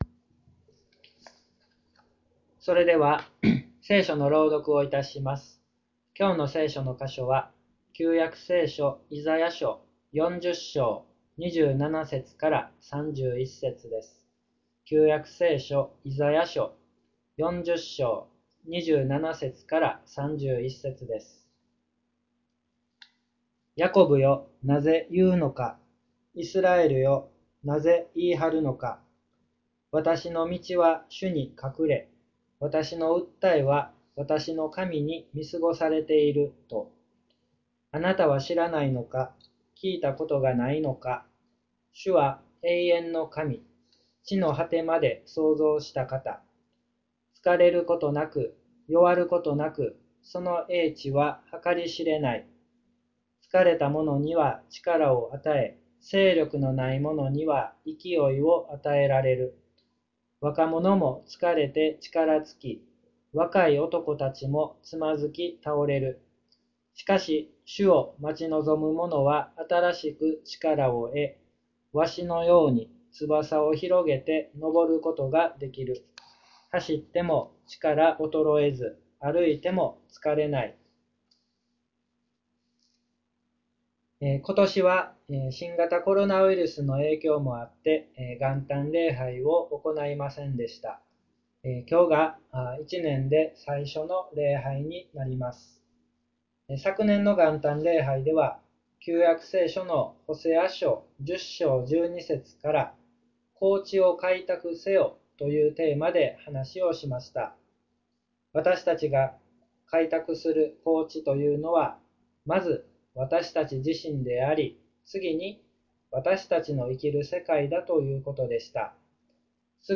礼拝説教から ２０２１年１月３日